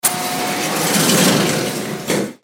دانلود آهنگ کشتی 5 از افکت صوتی حمل و نقل
جلوه های صوتی
دانلود صدای کشتی 5 از ساعد نیوز با لینک مستقیم و کیفیت بالا